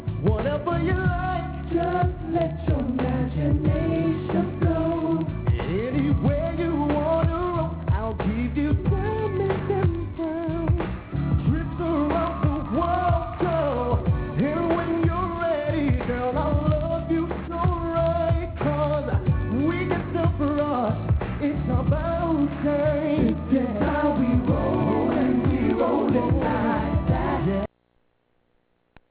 Live Clips
These are various clips from different performances